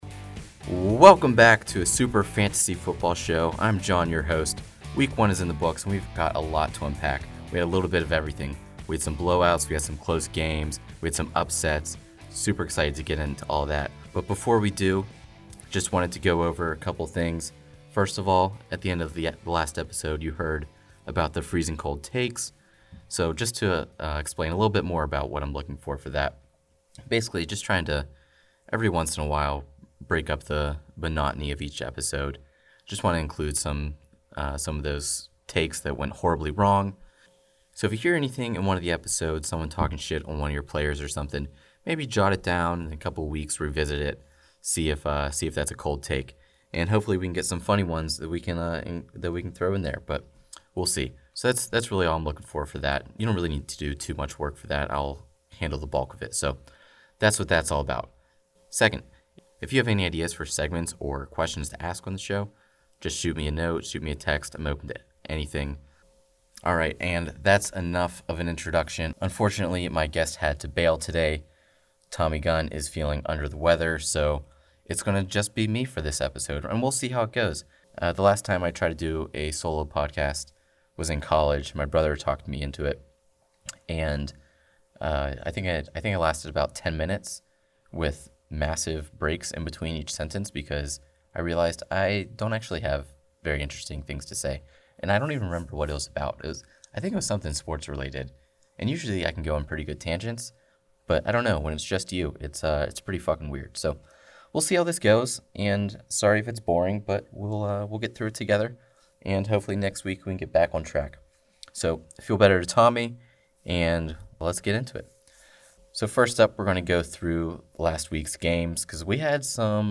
One podcast host!